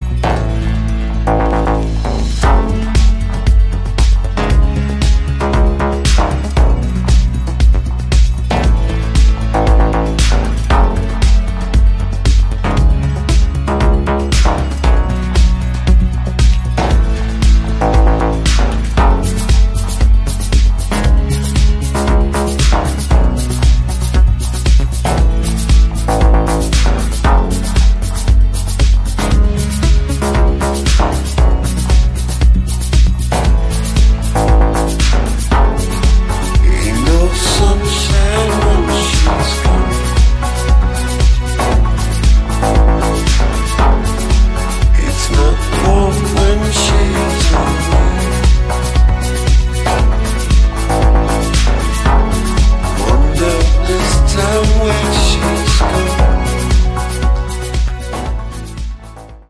Sonnerie Gratuite Sonnette
électronique